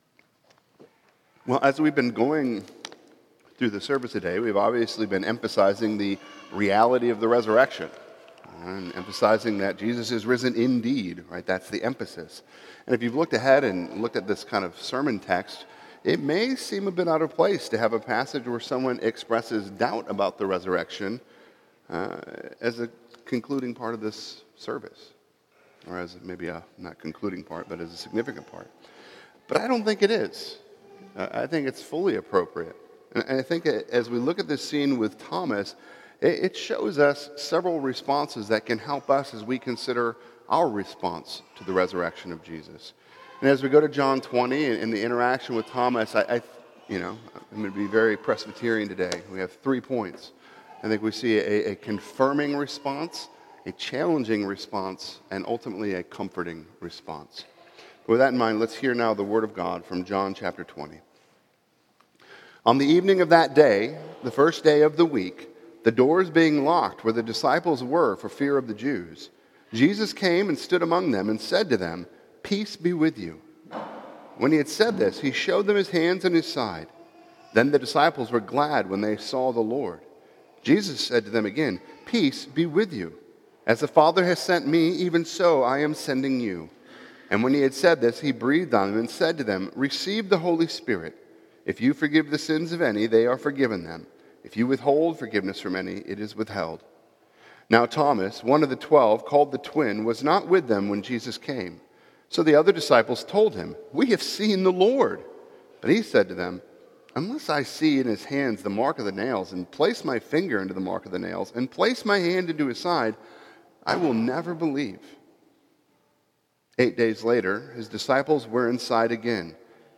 This is a sermon from guest preacher